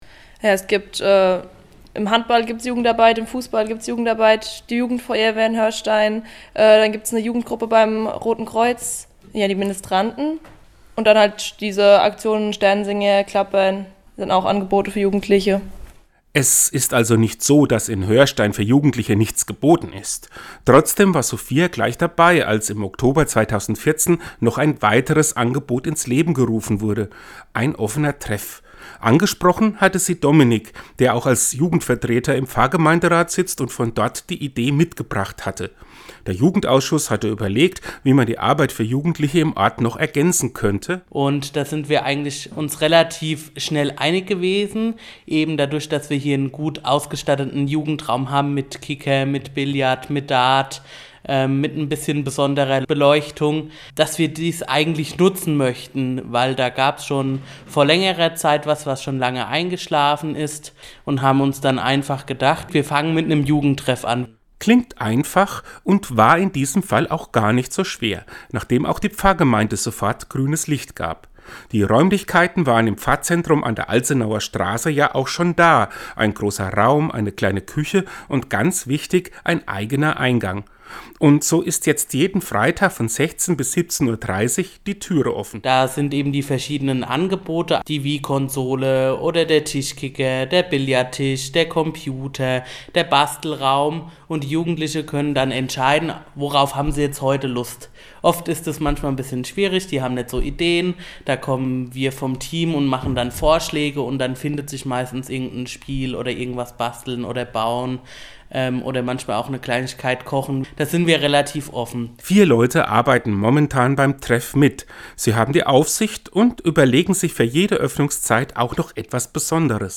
Seinen Radiobeitrag finden Sie unten als Download!